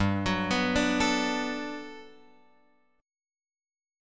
G Major 9th